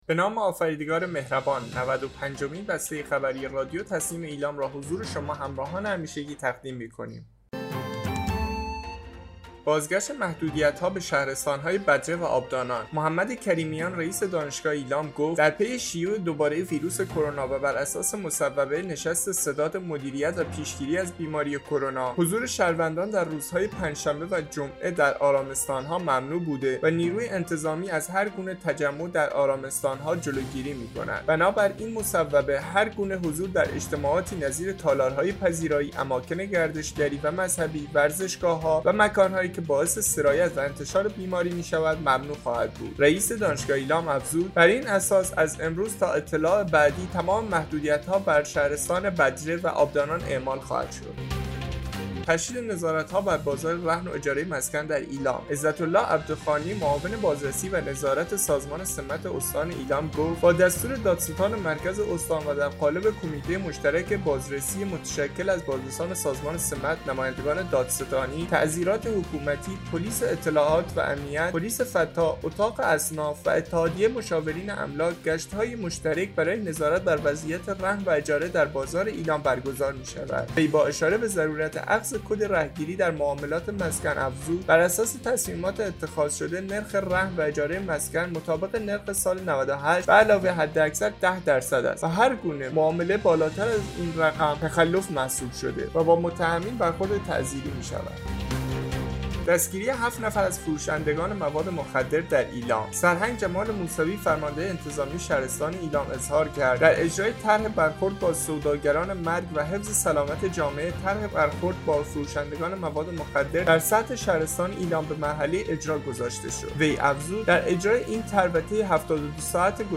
به گزارش خبرگزاری تسنیم از ایلام, نود و پنجمین بسته خبری رادیو تسنیم استان ایلام با خبرهایی چون؛ بازگشت محدودیت‌ها به شهرستان‌های بدره و آبدانان، تشدید نظارت‌ها بر بازار رهن و اجاره مسکن در ایلام، دستگیری 7 نفر از فروشندگان موادمخدر در ایلام و احداث 60 کلاس درس با مشارکت بنیاد برکت در ایلام، منتشر شد.